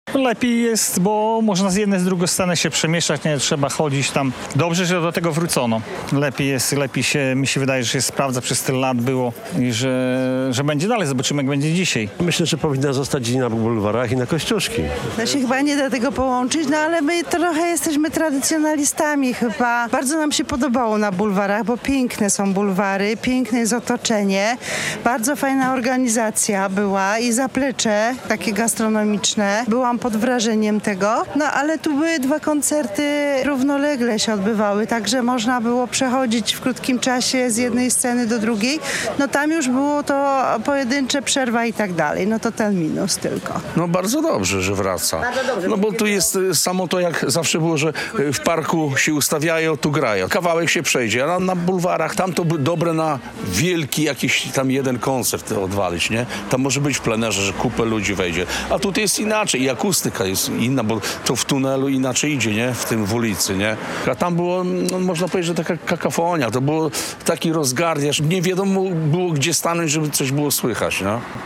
Suwałki Blues Festival - główna scena imprezy ponownie w centrum miasta - relacja